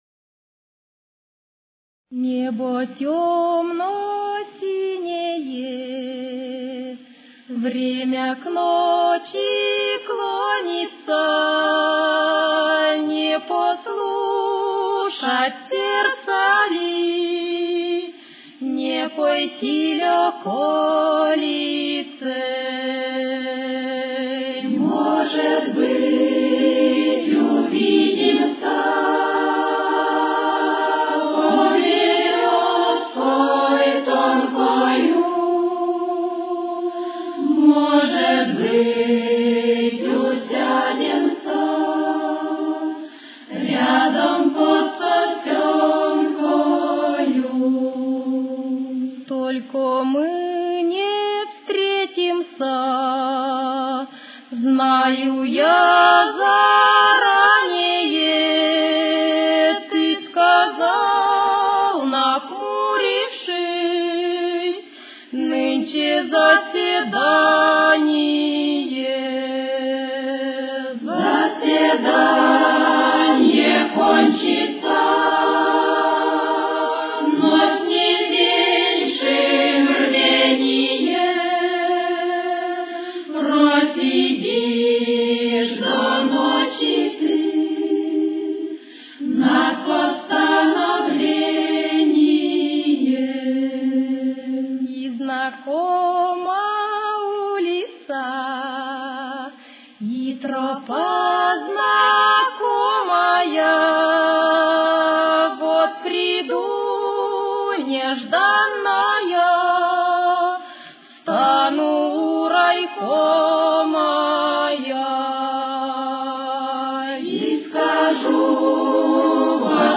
Темп: 68.